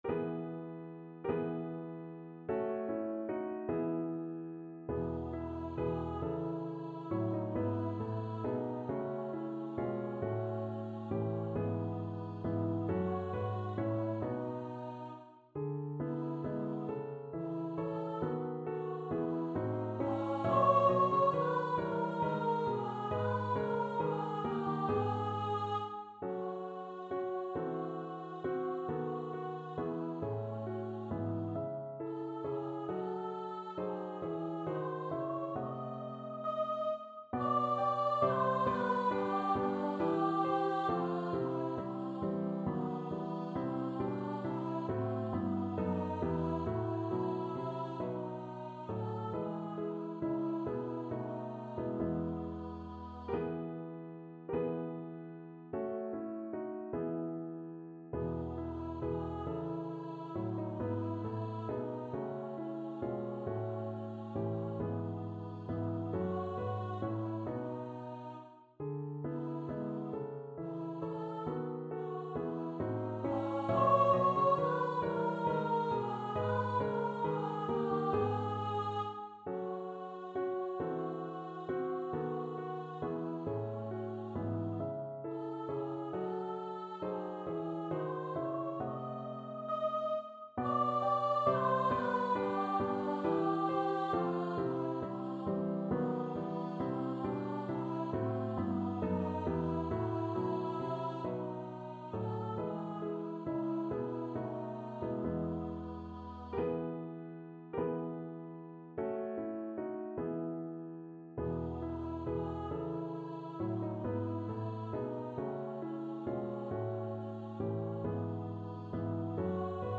Free Sheet music for Choir (SATB)
3/8 (View more 3/8 Music)
Allegretto (. = 50)
Classical (View more Classical Choir Music)